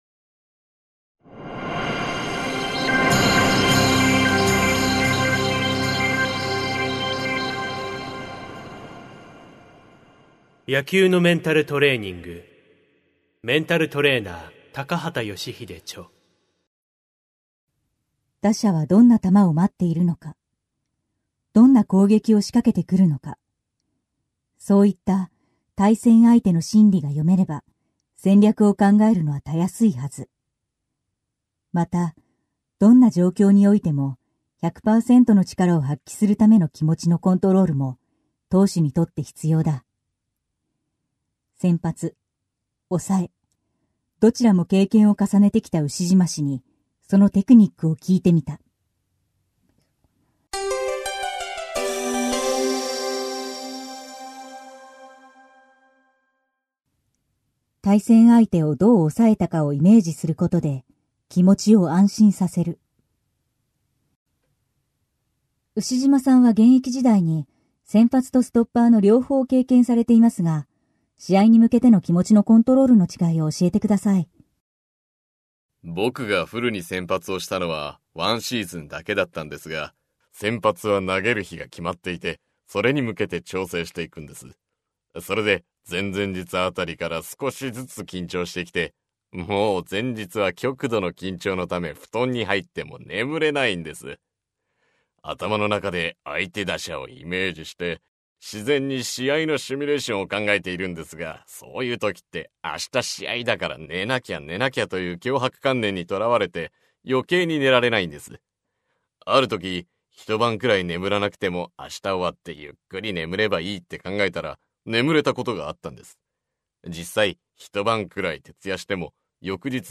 [オーディオブック] 野球のメンタルトレーニング